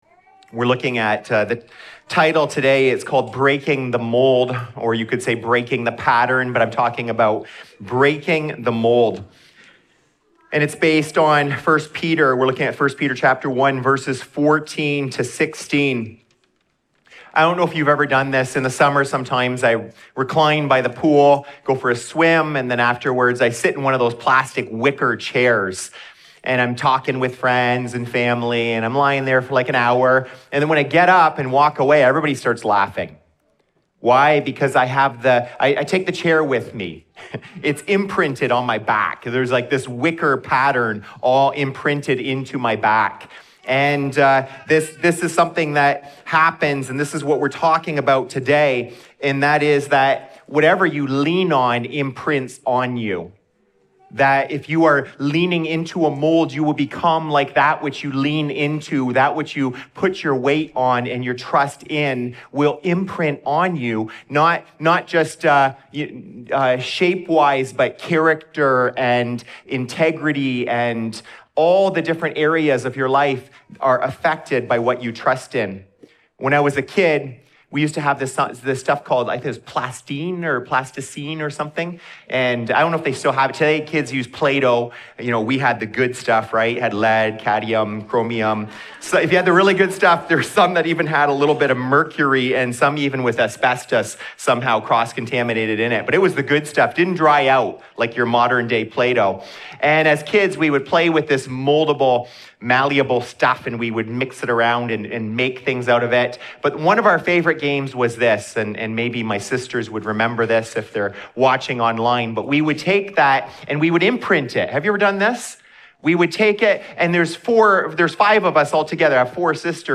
This sermon walks through what it means to have a new family, new desires, and a new pattern—and why breaking the old mold starts with the gospel.